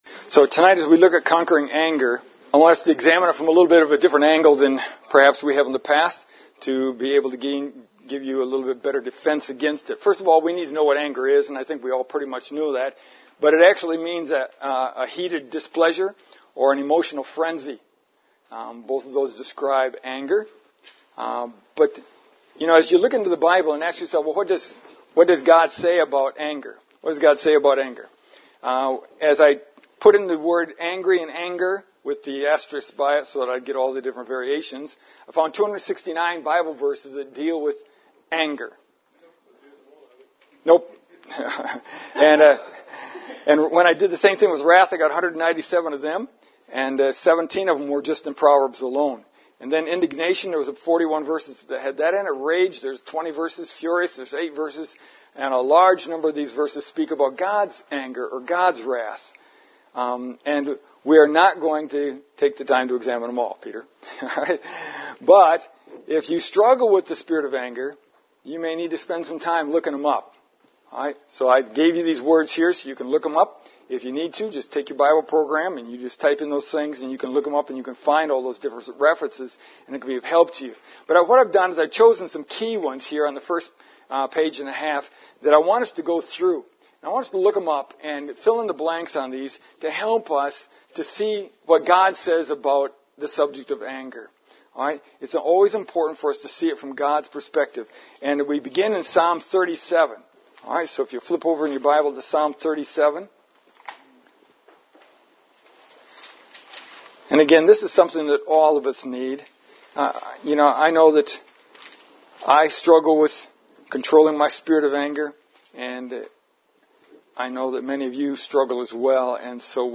Our Wednesday service is informal and I have people read scriptures so it is more difficult to hear on the audio.